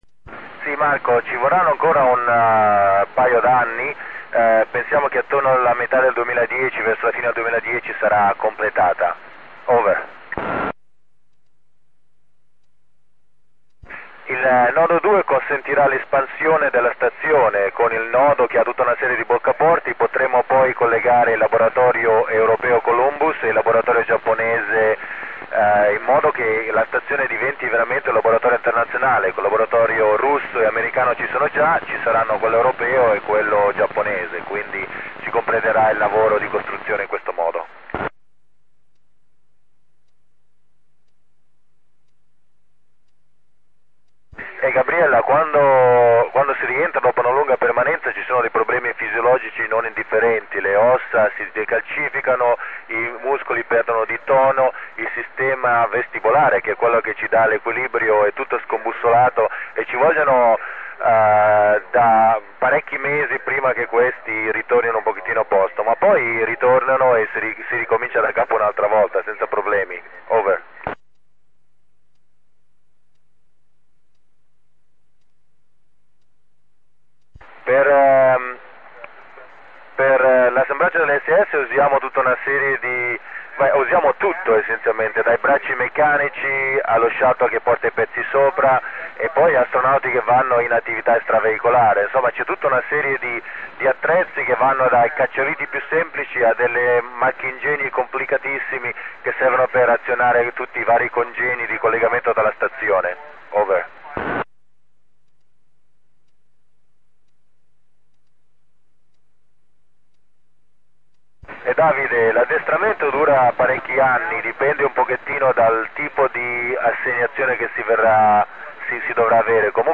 Rx TS-2000 / FT-817 ant. Turnstile